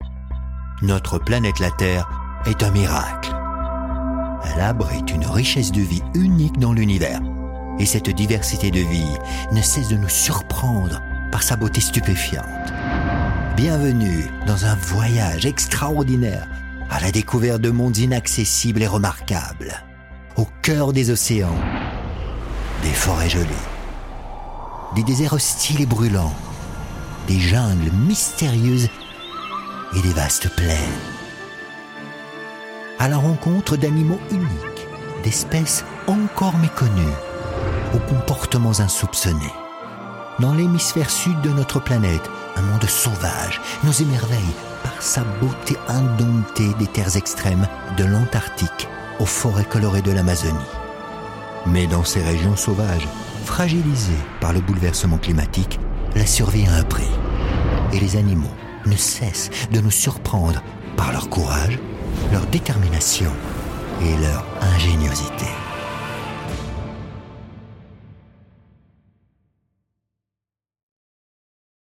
Voix off
Demo Voix OFF Planète Bleue